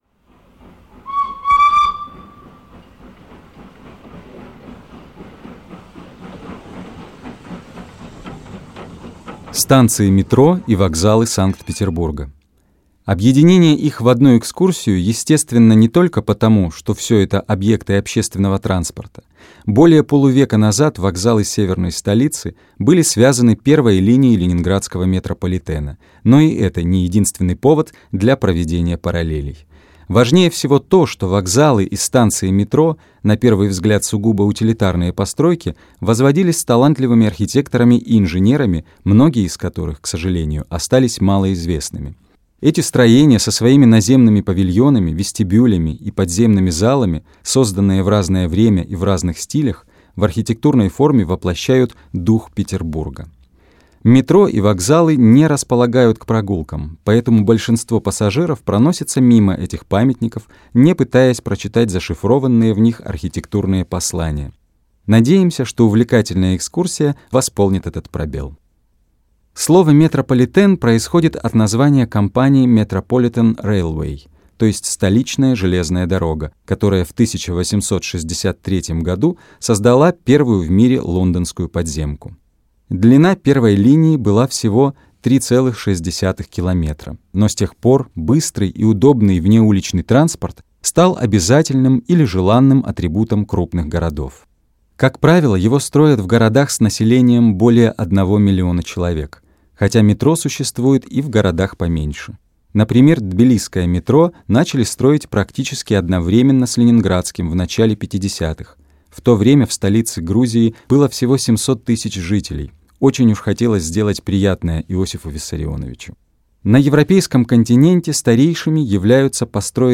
Аудиокнига Метро и вокзалы Санкт-Петербурга | Библиотека аудиокниг